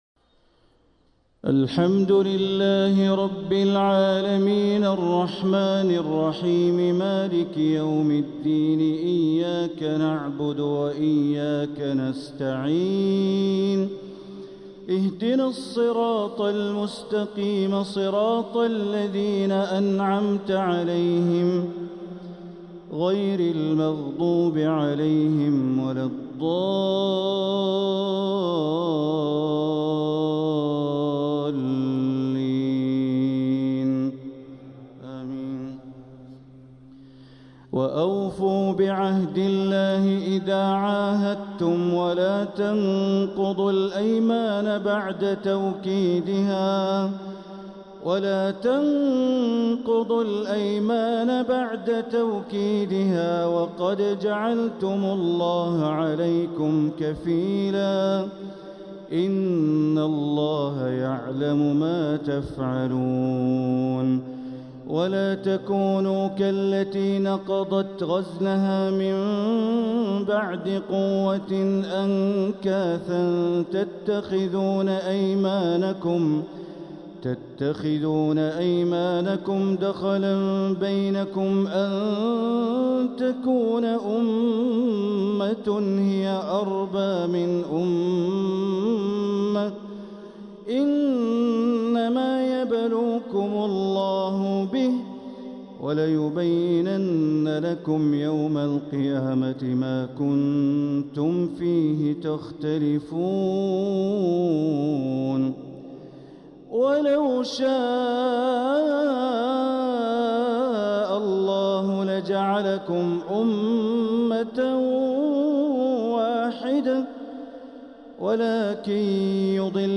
صلاة الجمعة 6 شوال 1446هـ من سورة النحل 91-96 | Jumu'ah prayer from Surah An-Nahl 4-4-2025 > 1446 🕋 > الفروض - تلاوات الحرمين